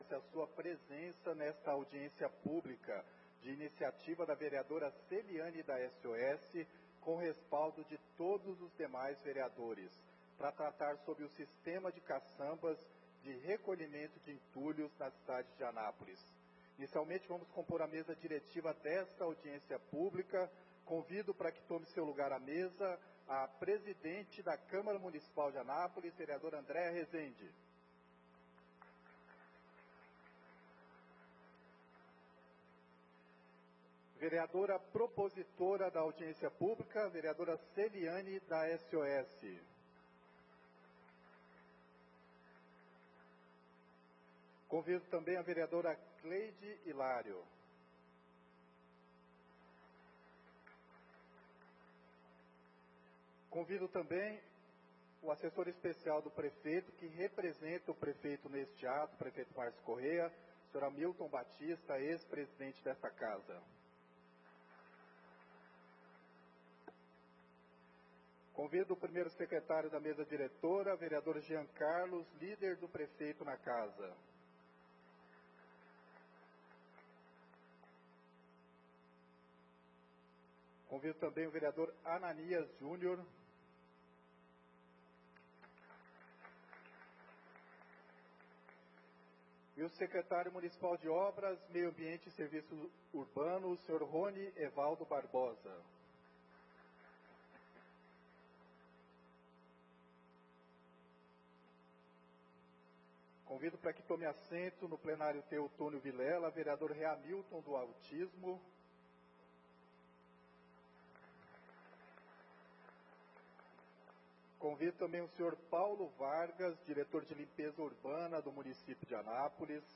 Audiência Pública Caçambeiros de Anápolis. Dia 20/02/2025.